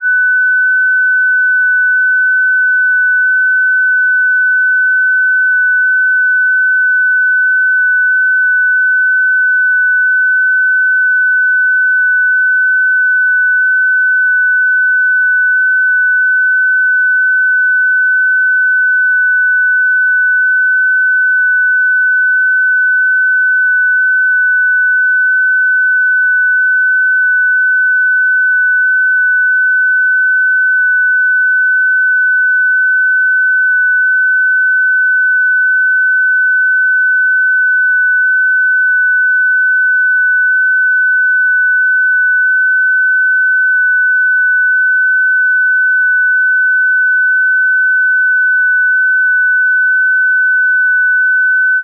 FST4, 60-sec mode
FST4-60.ogg